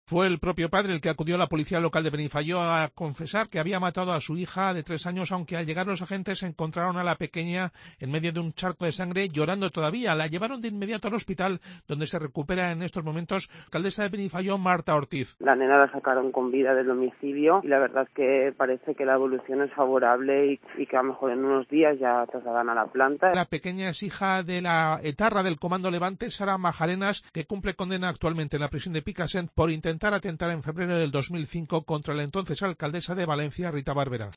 El audio incluye las declaraciones de la alcaldesa de Benifaió, Marta Ortiz dónde fue detenido el hombre